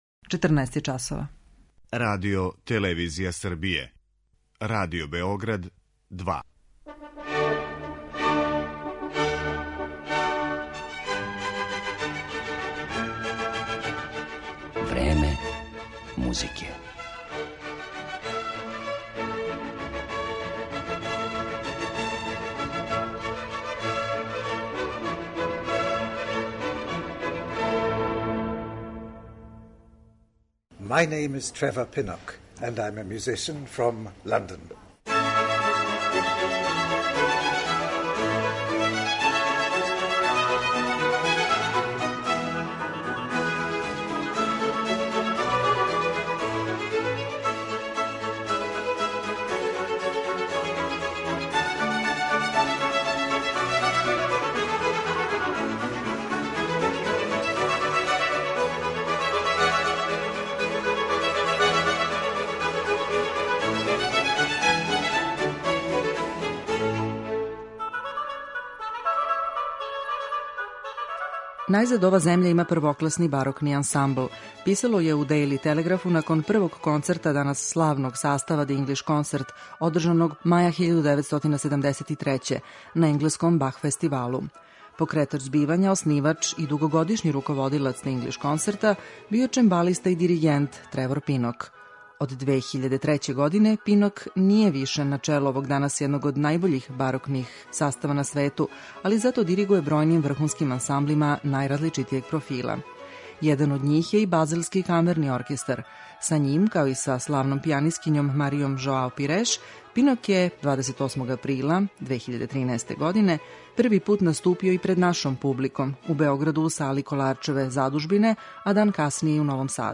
У Времену музике ћемо га ипак претежно представити у репертоару са којим се прославио и слушаћете га како изводи дела барокних мајстора. Емитоваћемо и интервју снимљен са Тревором Пиноком у Београду априла 2013. године.